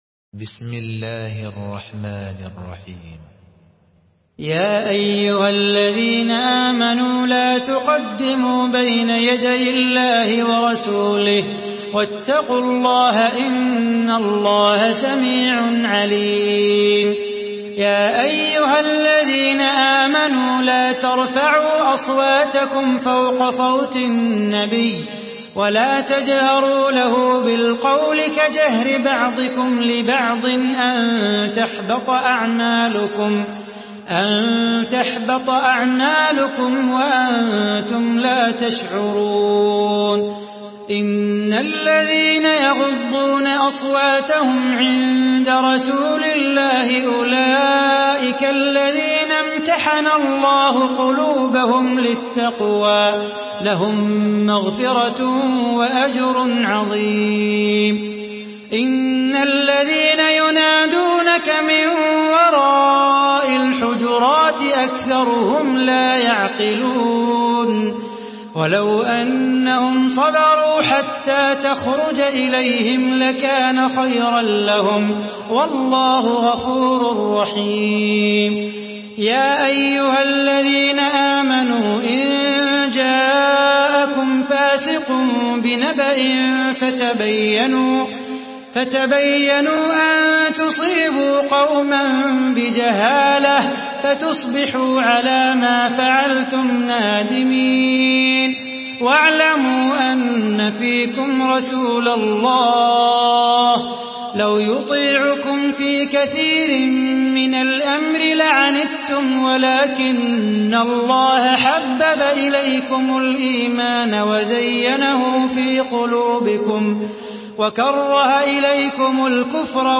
Murattal Hafs An Asim